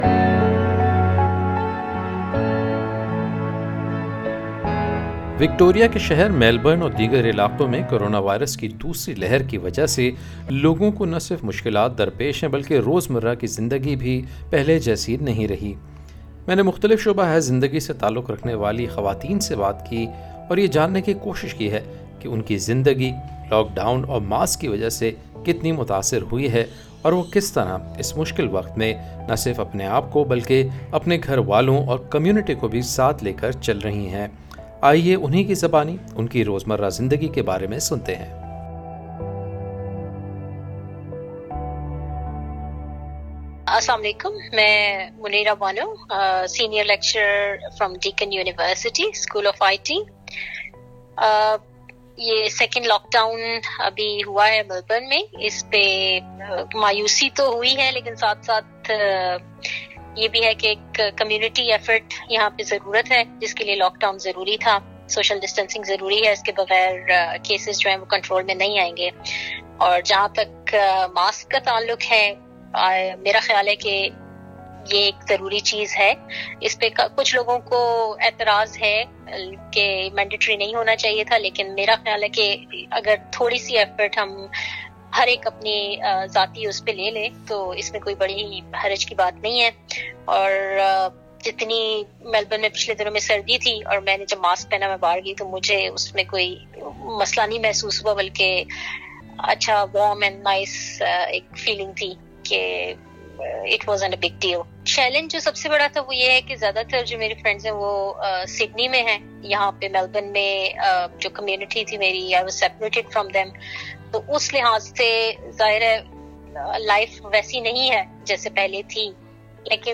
Five women from different backgrounds share their second wave experiences and how they are trying to make a difference to the people around them.